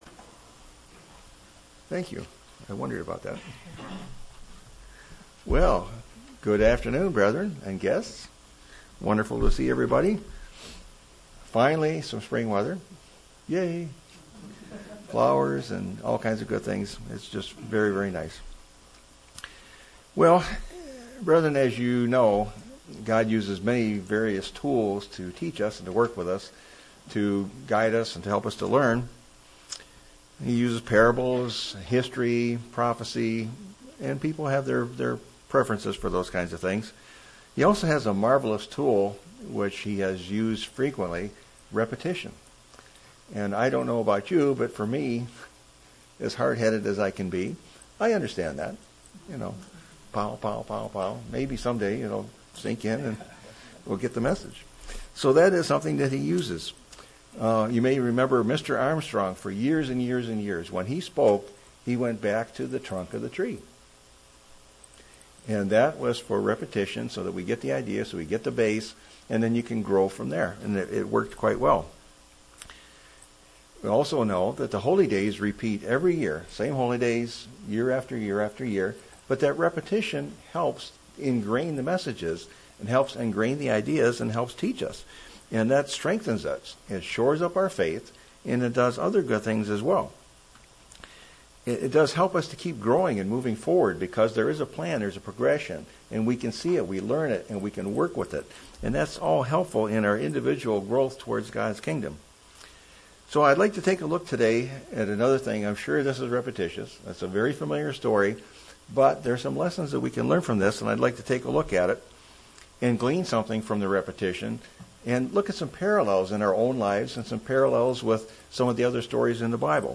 Sermon B 4-27-13.mp3